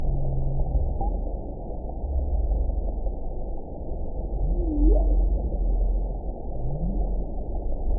event 919684 date 01/16/24 time 18:56:45 GMT (1 year, 10 months ago) score 7.90 location TSS-AB08 detected by nrw target species NRW annotations +NRW Spectrogram: Frequency (kHz) vs. Time (s) audio not available .wav